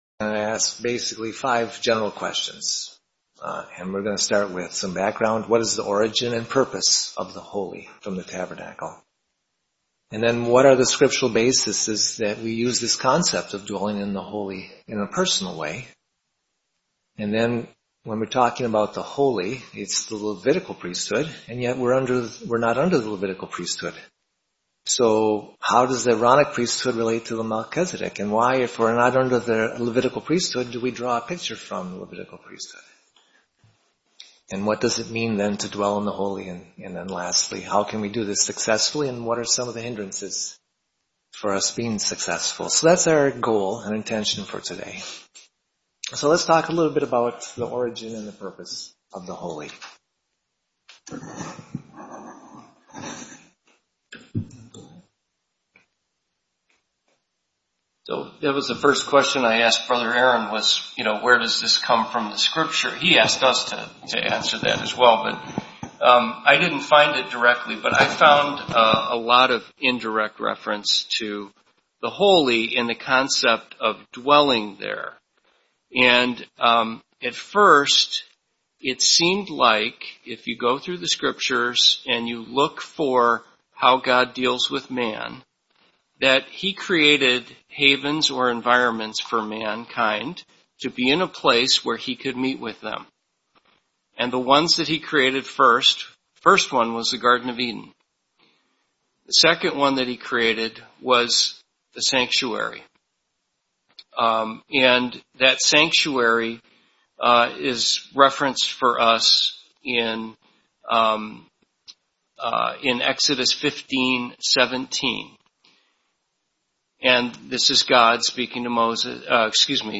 Service Type: Panel Discussions